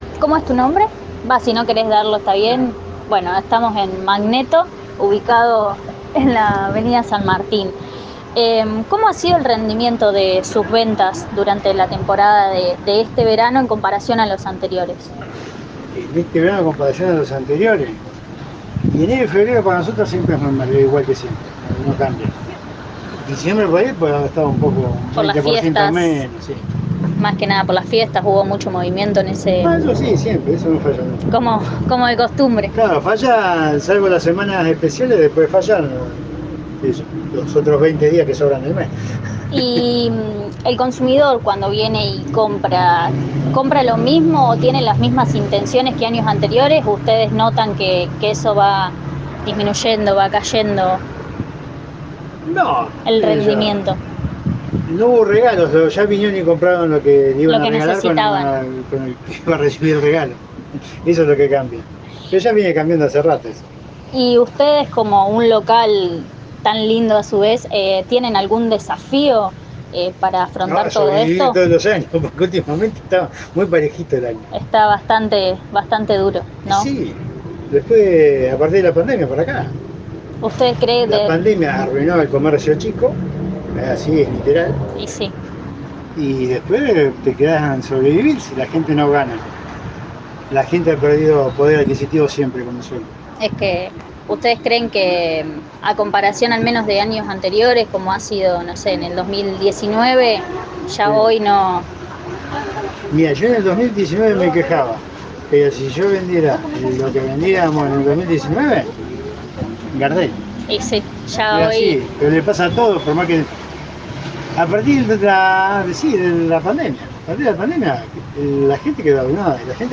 Sección urbana, donde se habla con las personas para saber su perspectivas en torno a distintas cosas, en este caso el consumo en los locales.